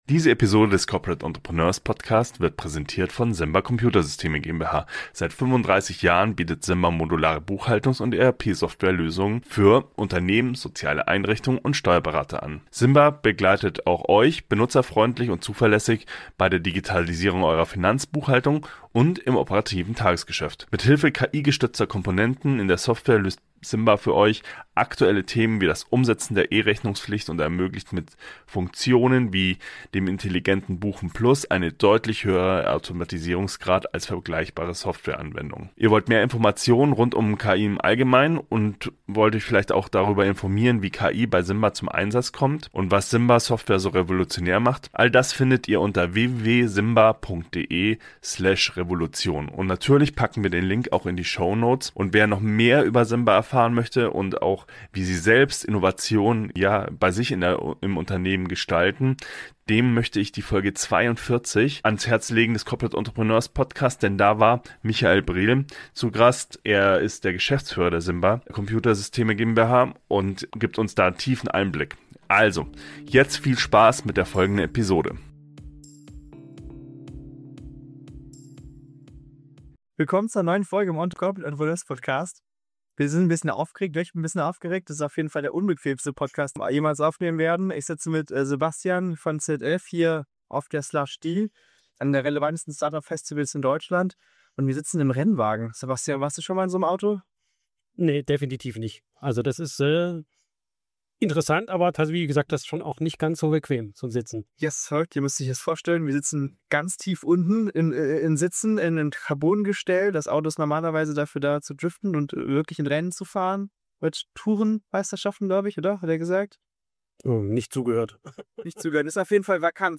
Diese Folge des Corporate Entpreneurs Podcasts (live aufgenommen auf der slush'd in Heilbronn) dreht sich um Innovationsarbeit im Konzern.